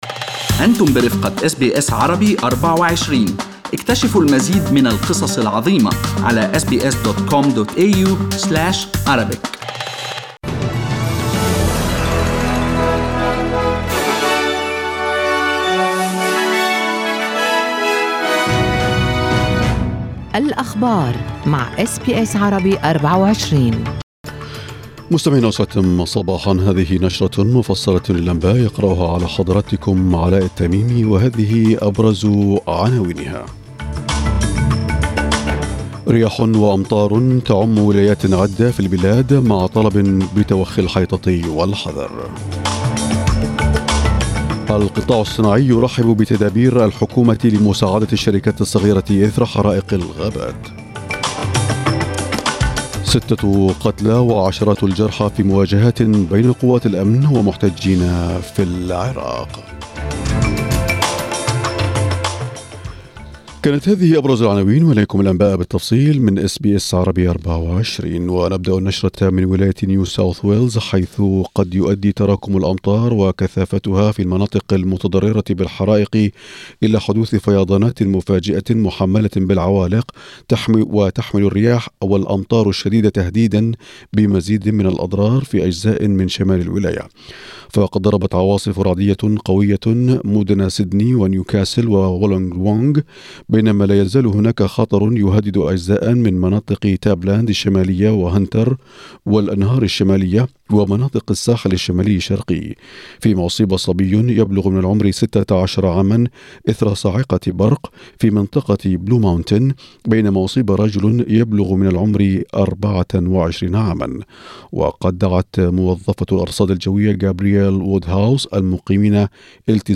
نشرة أخبار الصباح 21/01/2020
Arabic News Bulletin Source: SBS Arabic24